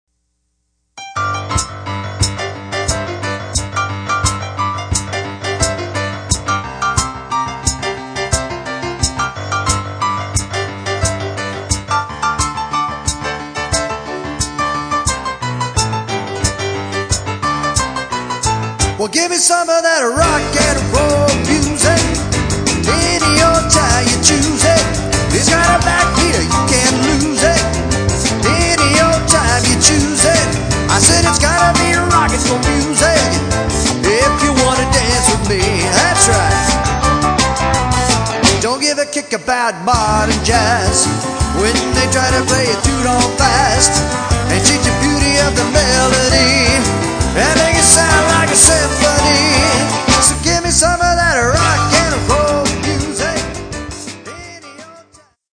Oldies / Rock